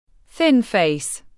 Thin face /θɪn feɪs/